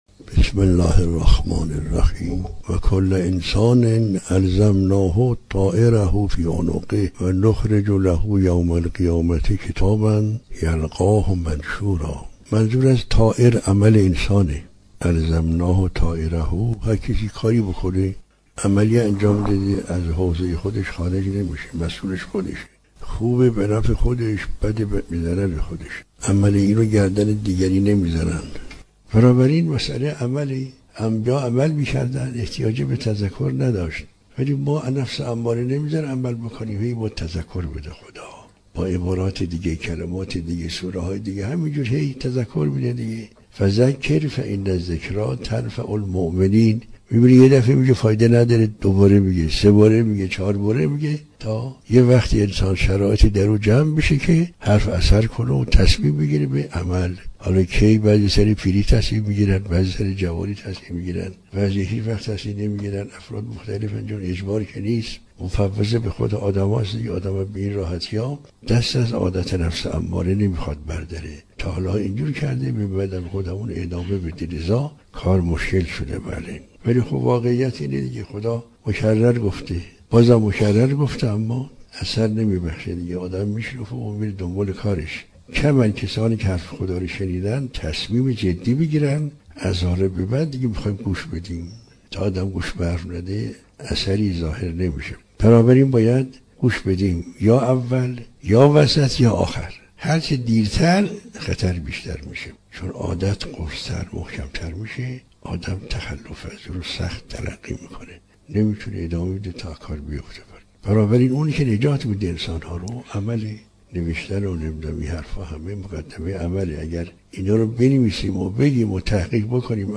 درس اخلاق | دانستن بدون عمل، نجات‌بخش نیست + صوت